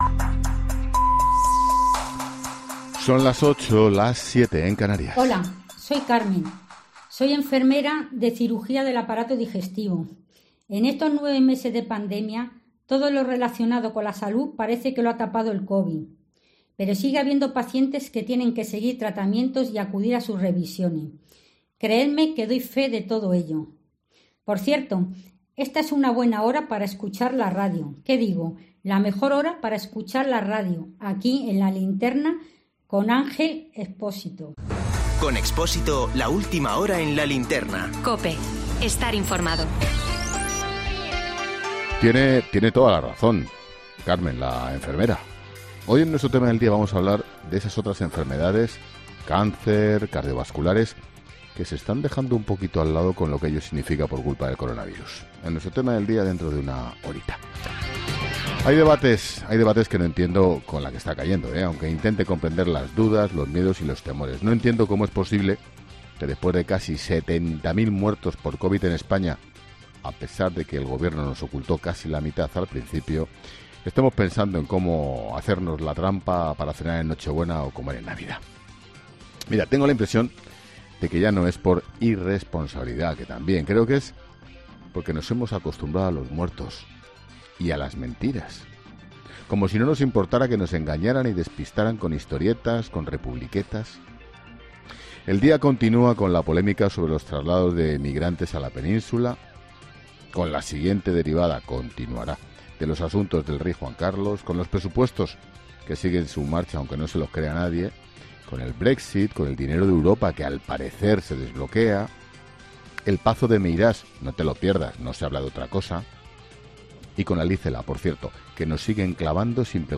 Monólogo de Expósito
El director de 'La Linterna', Ángel Expósito, analiza en su monólogo la previsión de estas Navidades